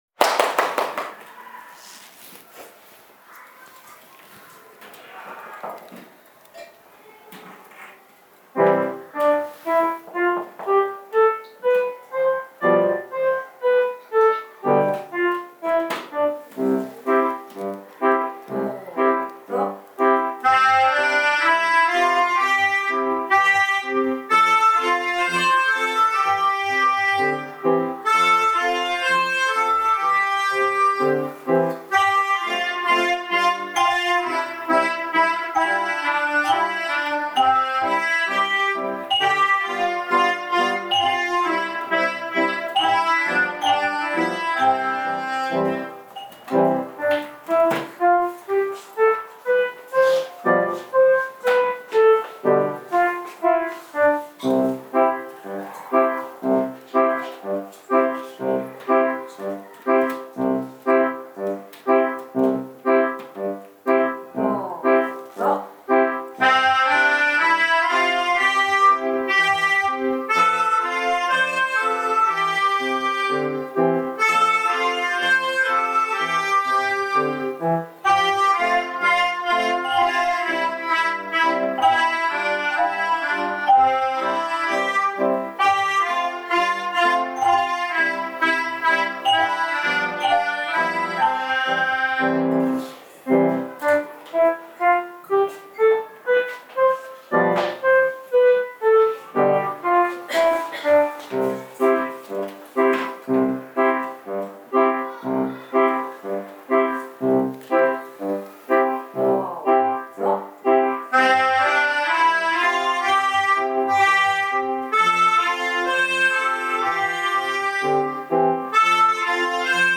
3月14日(木)、2年生は、生活科の学習で、この一年間の自分の成長をふり返りって発表会を開きました。
12人の息がぴたっと合った楽しい演奏でした。
2年合奏｢こぎつね｣.MP3